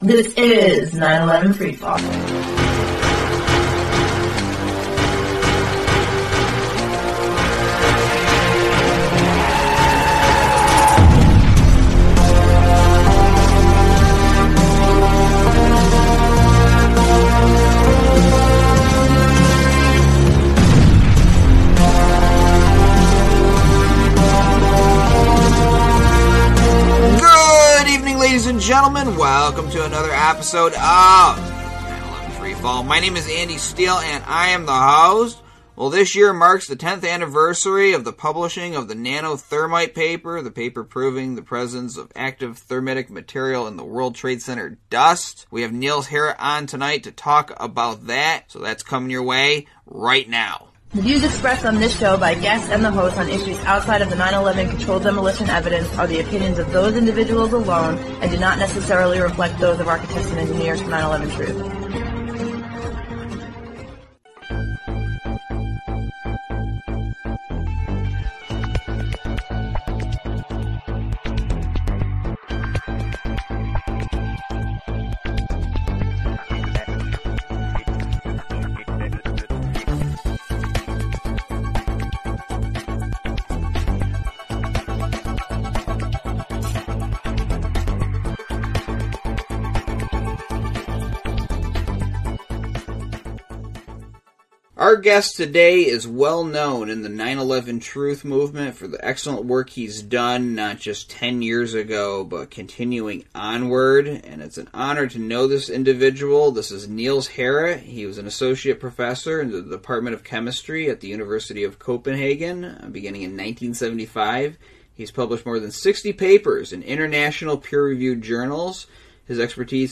Talk Show Episode
Each episode, we interview one of the many researchers and activists who are blowing the lid off the crime of the century.